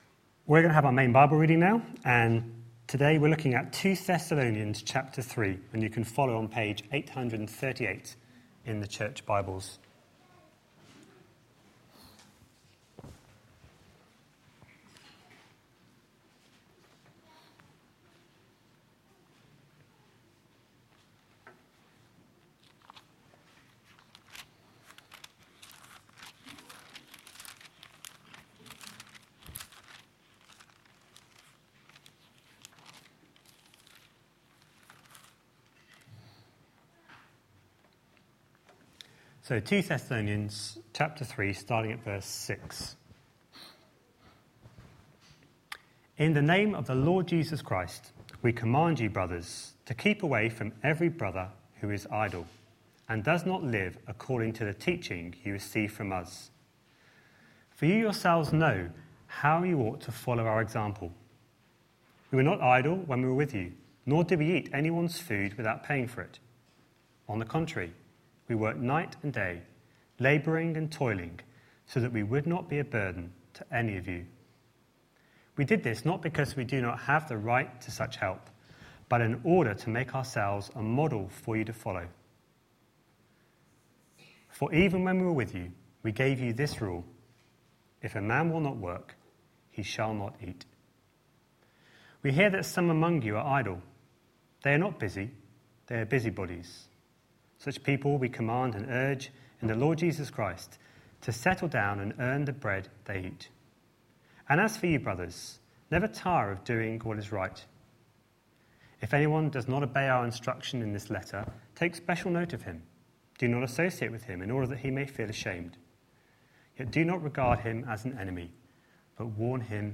A sermon preached on 11th January, 2015, as part of our Work series.